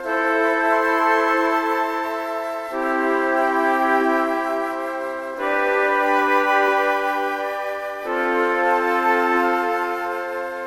神奇的长笛
标签： 90 bpm Orchestral Loops Flute Loops 1.80 MB wav Key : C
声道立体声